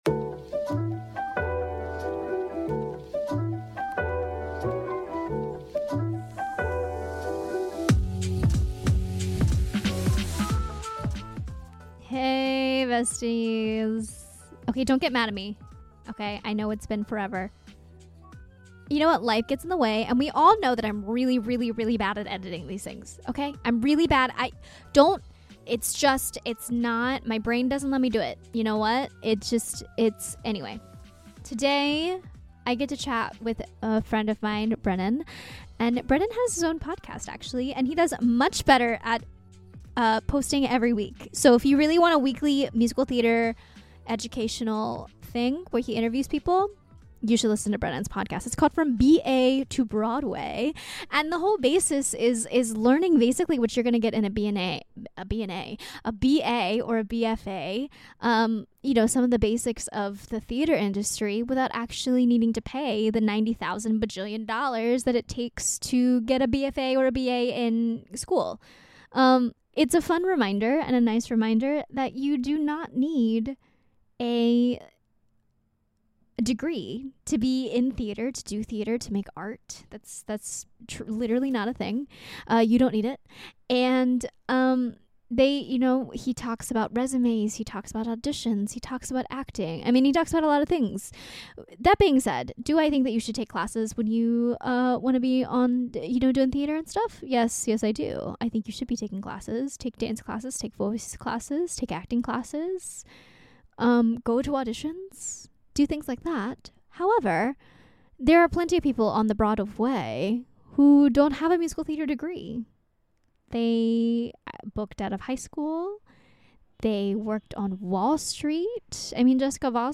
🎤 Shure SM58 with Focusrite